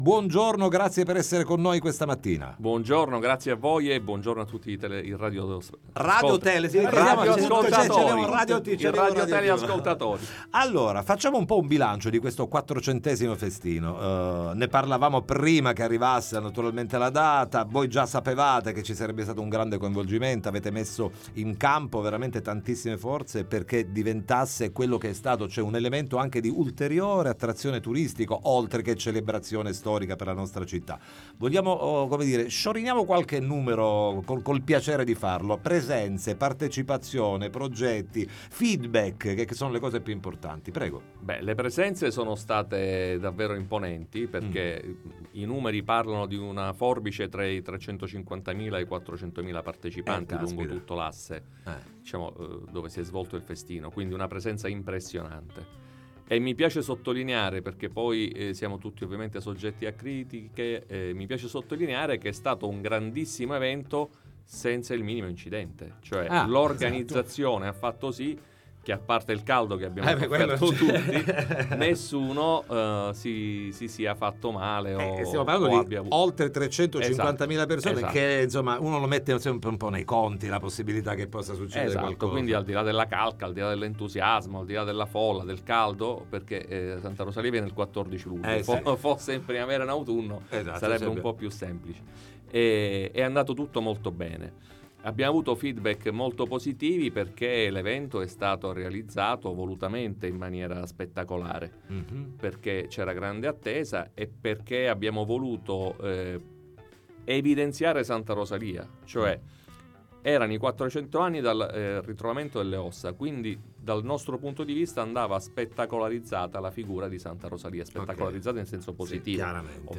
Il post festino 2024, ne parliamo con Giampiero Cannella, Vicesindaco di Palermo